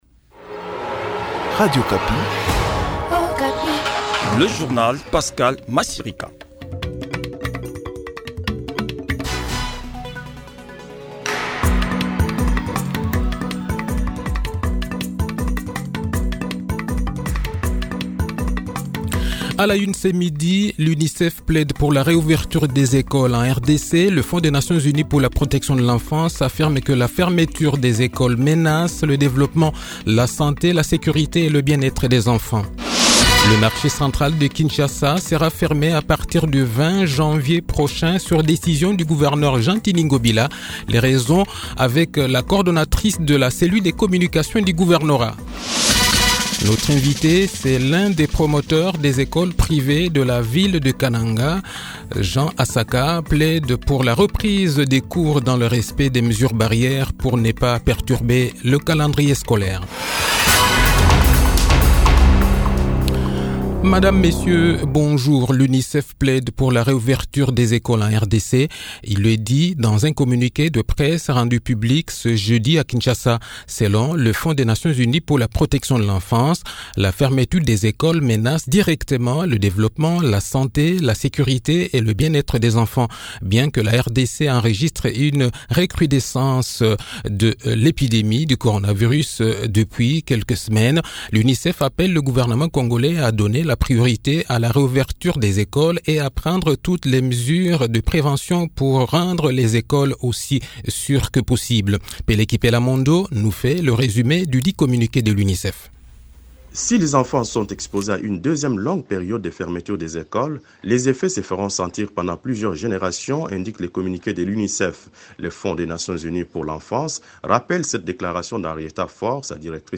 Le journal de 12 h, 14 Janvier 2021